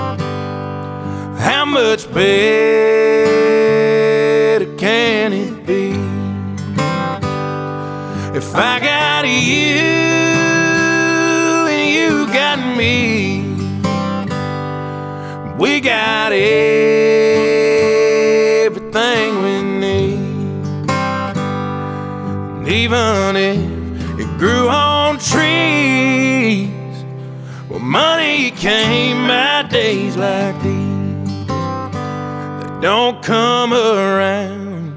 2025-10-03 Жанр: Кантри Длительность